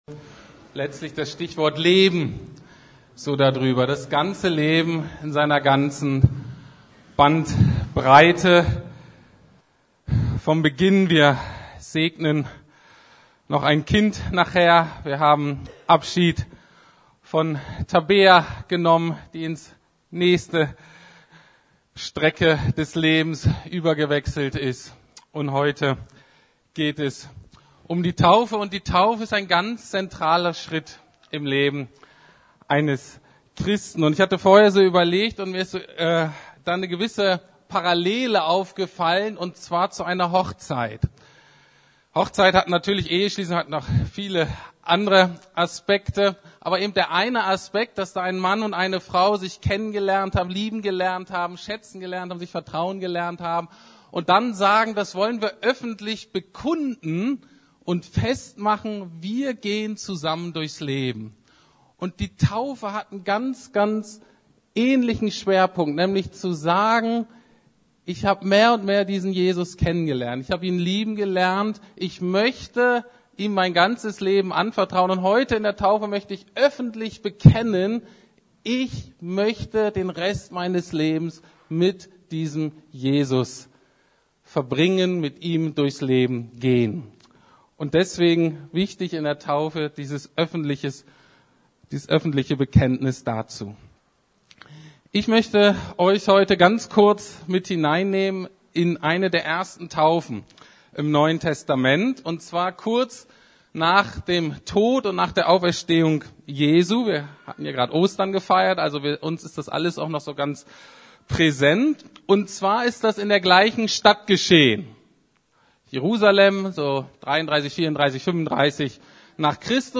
Taufe ~ Predigten der LUKAS GEMEINDE Podcast